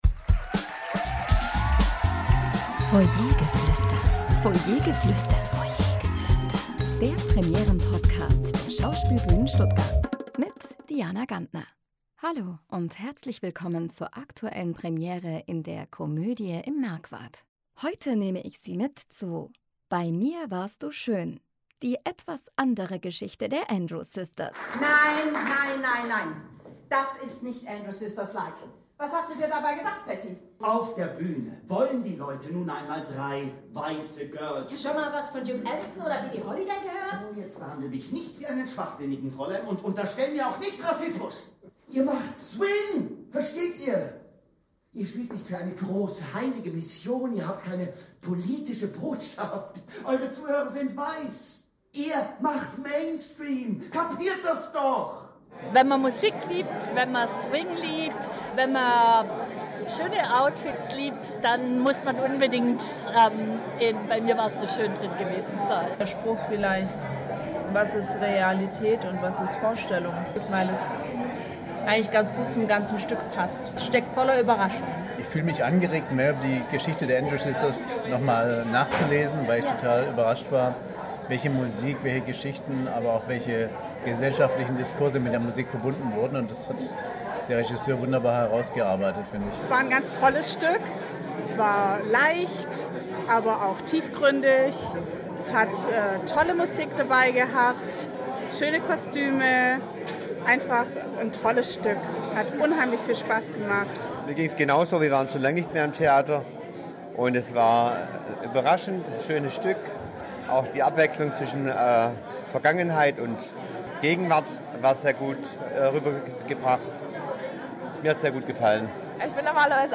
Publikumsstimmen zur Premiere von “Bei mir warst du schön”